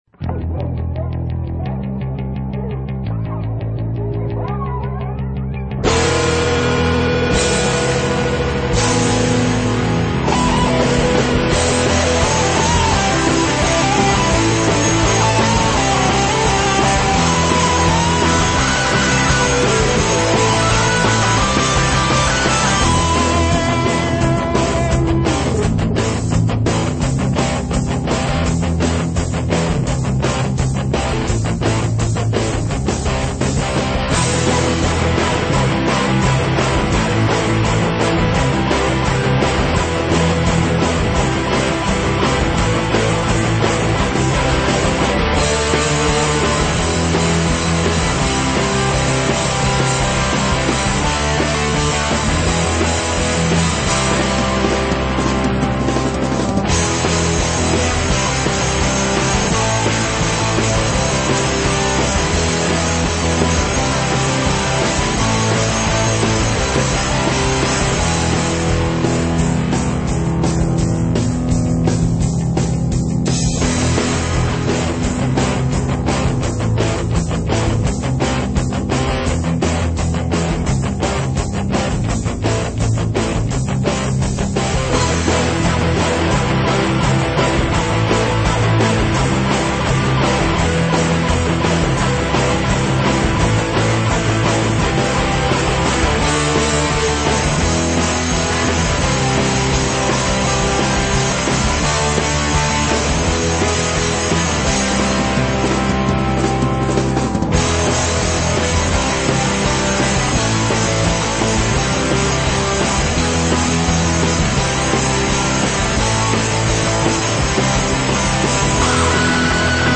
metal
rock
punk
hard rock
LoFi Mp3
high energy rock and roll